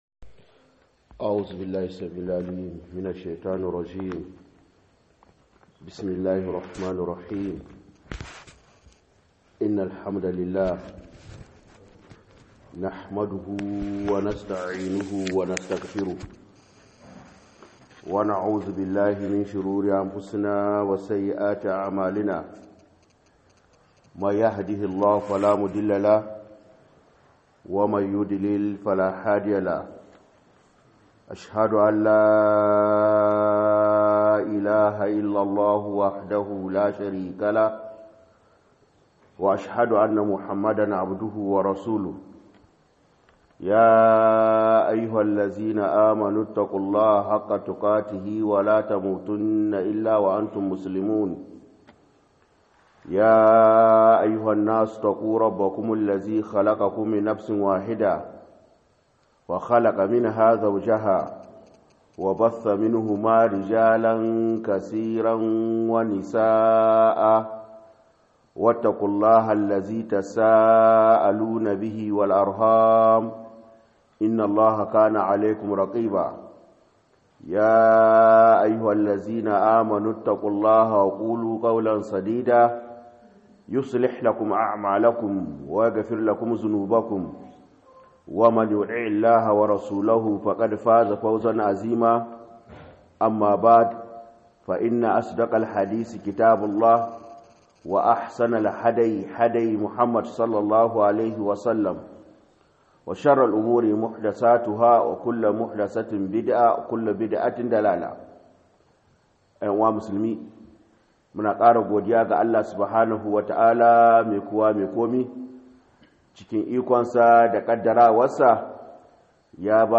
02 NASIHA AKAN WATAN RAJAB - MUHADARA